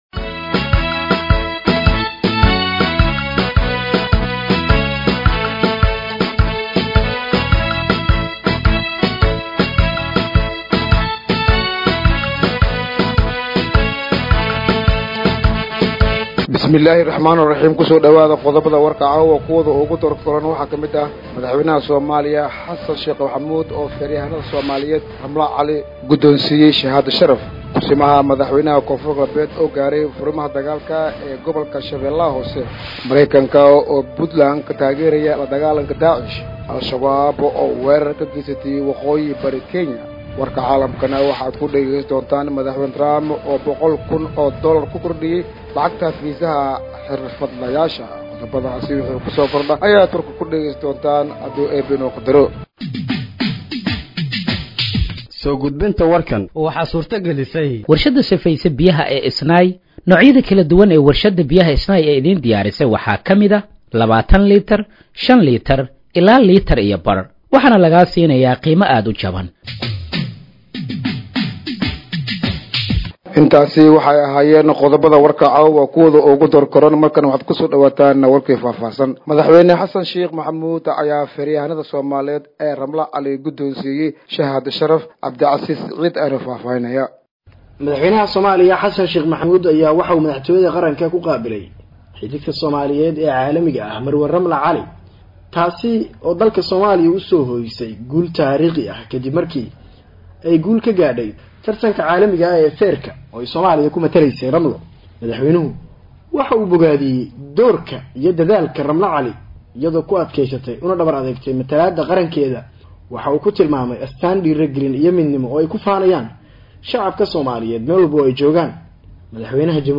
Dhageeyso Warka Habeenimo ee Radiojowhar 20/09/2025
Halkaan Hoose ka Dhageeyso Warka Habeenimo ee Radiojowhar